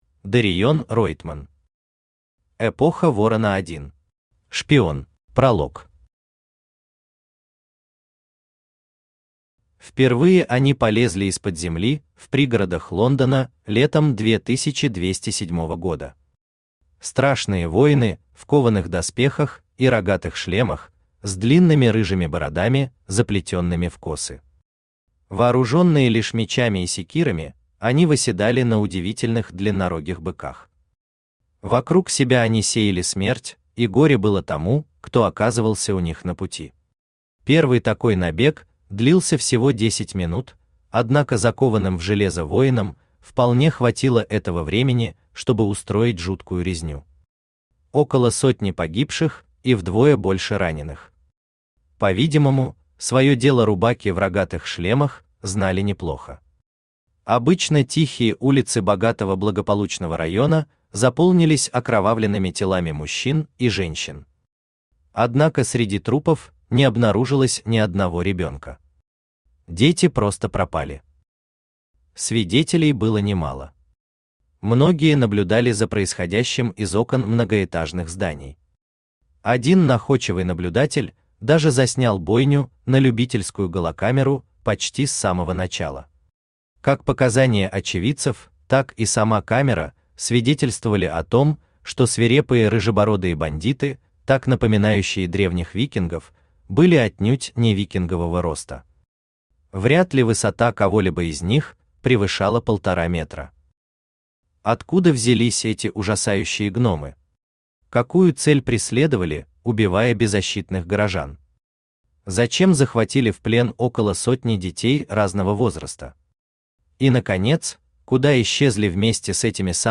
Шпион Автор Дариен Ройтман Читает аудиокнигу Авточтец ЛитРес.